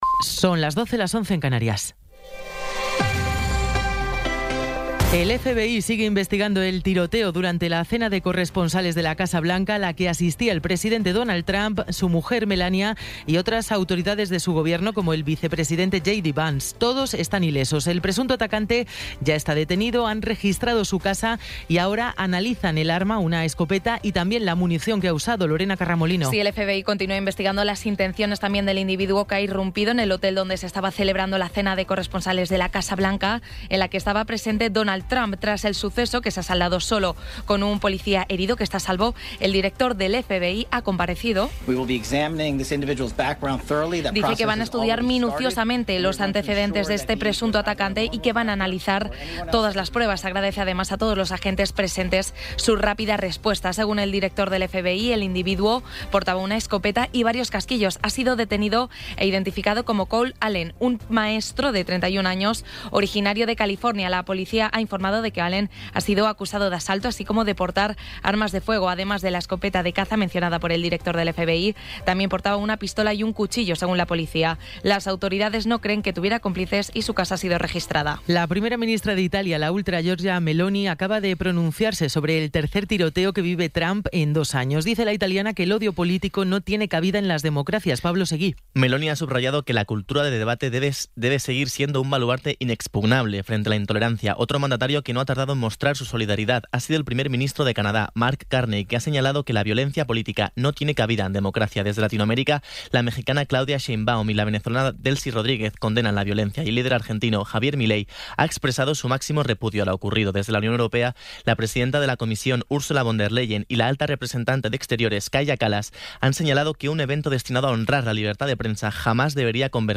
Resumen informativo con las noticias más destacadas del 26 de abril de 2026 a las doce.